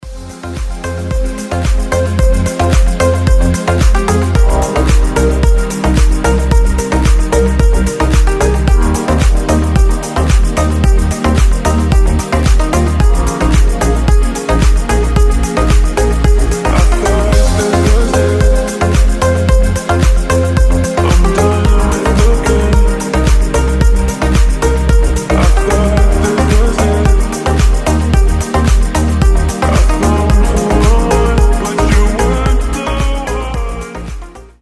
• Качество: 320, Stereo
красивые
deep house
мелодичные